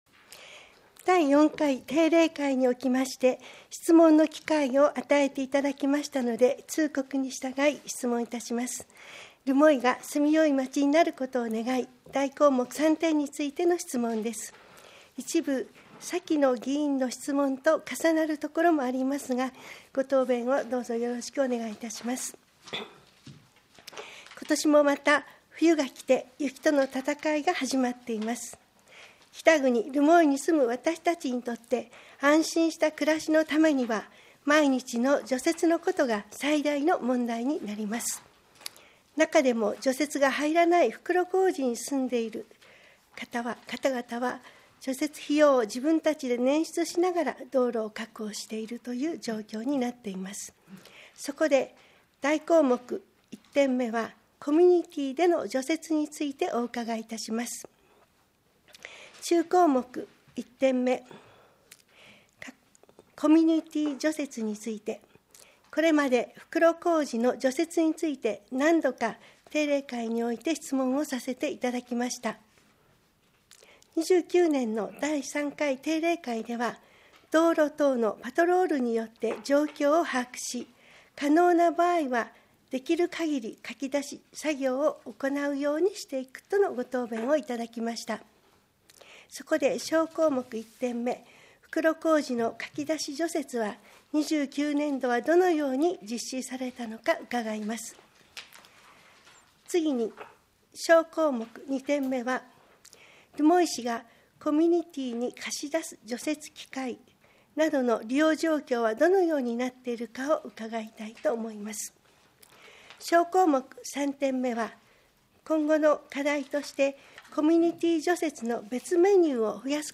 平成30年第4回定例会 一般質問録音音声 - 留萌市ホームページ
議会録音音声